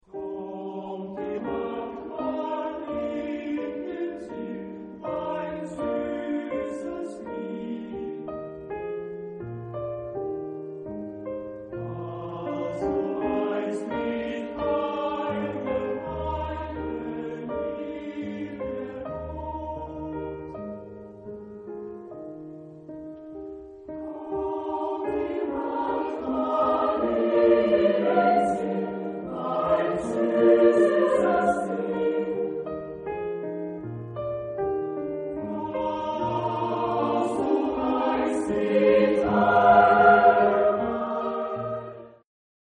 SATB (4 voices mixed).
Romantic.
Lied.